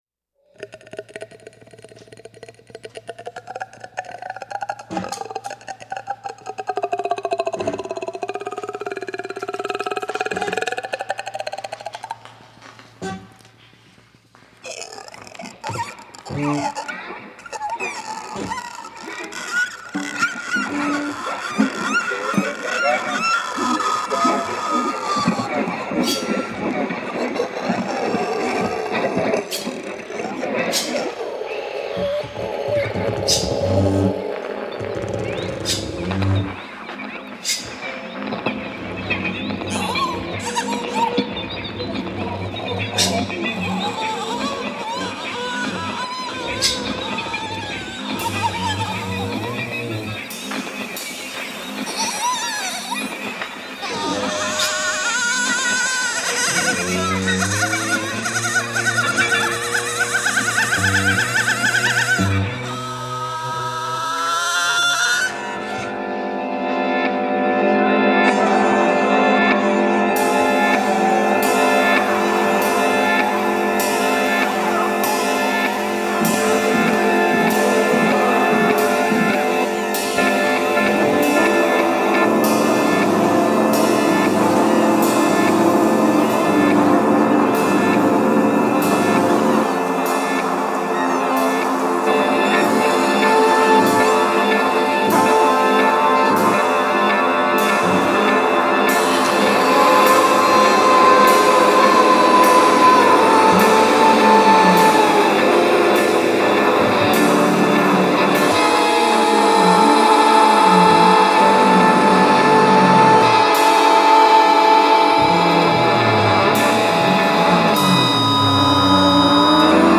a group improvisation